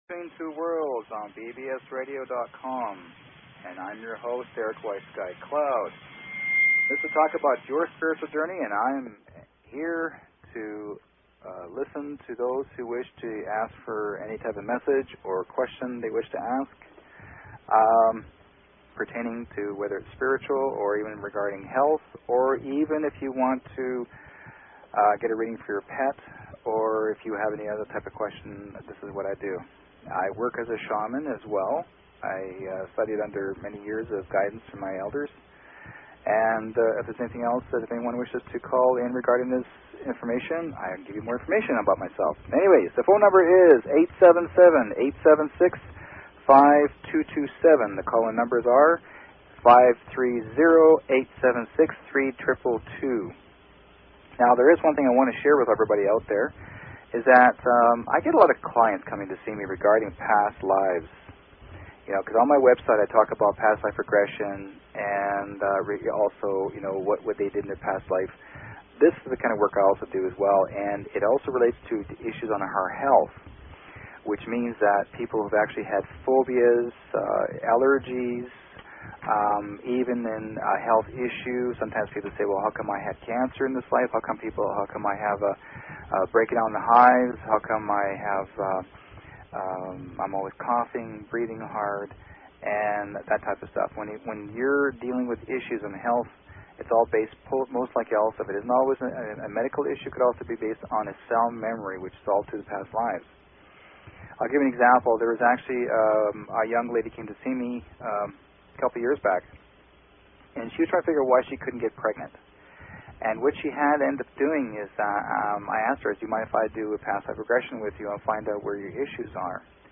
Talk Show Episode, Audio Podcast, Between_Two_Worlds and Courtesy of BBS Radio on , show guests , about , categorized as